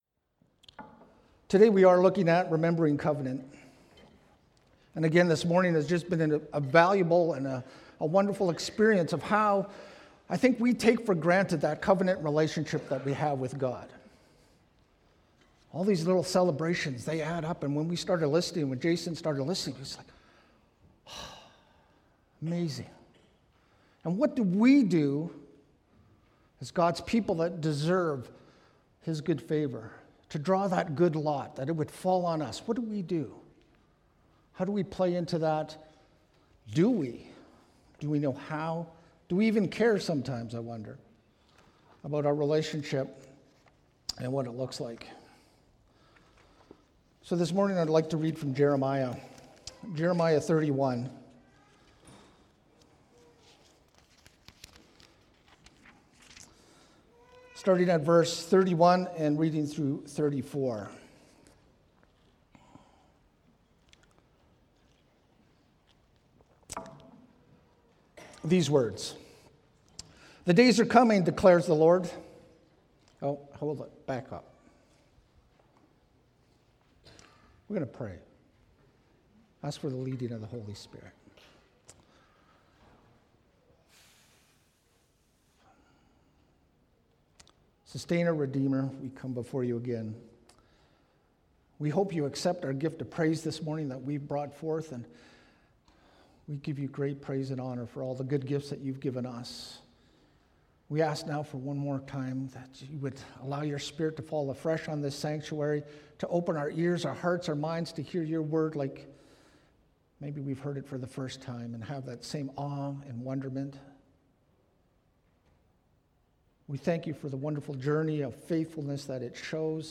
Sermons | Ebenezer Christian Reformed Church
2017 Guest Speaker Download Download Reference Jeremiah 31:31-34